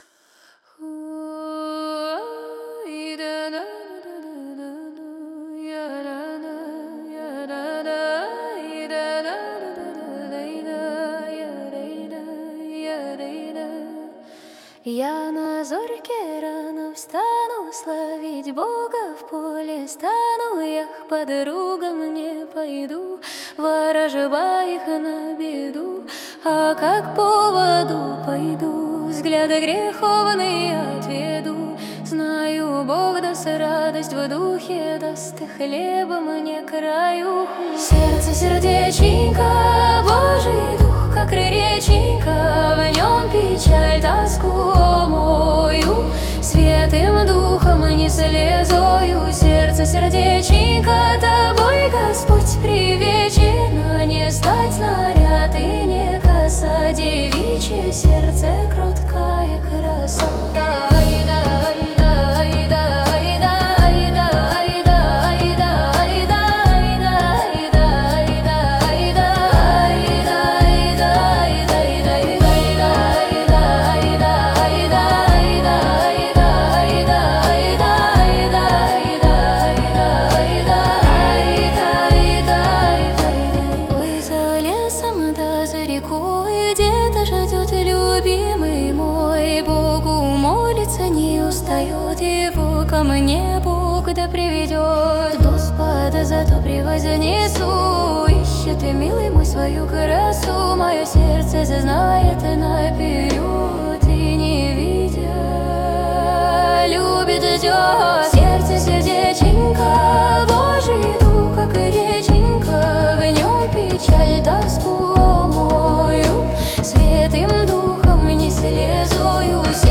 песня ai
179 просмотров 551 прослушиваний 46 скачиваний BPM: 84